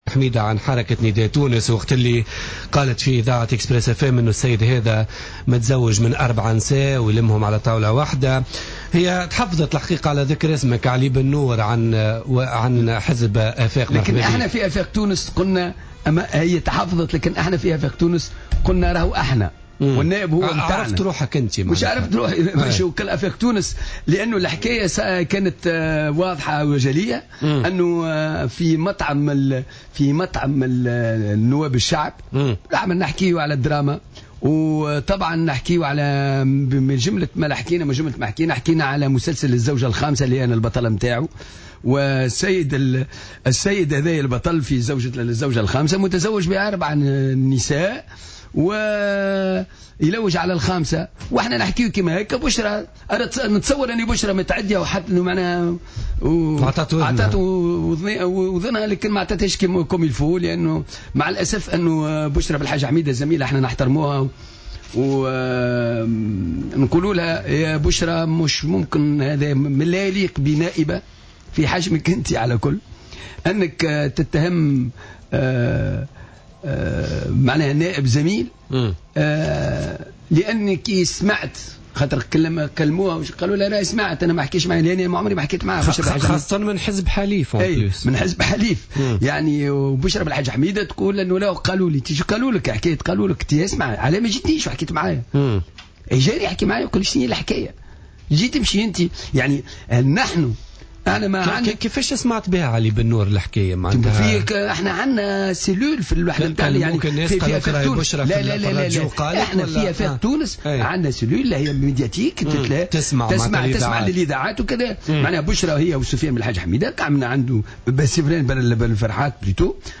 أكد النائب بمجلس نواب الشعب عن افاق تونس علي بنور ضيف بوليتيكا اليوم الإثنين 16 فيفري 2015 إن الخبر الذي أثارته النائب بشرى بلحاج حميدة في تصريح اذاعي الذي تتهمه فيه بزواجه ب4 زوجات لا أساس له من الصحة ولا يليق بنائب مثل بشرى بلحاج حميدة على حد قوله.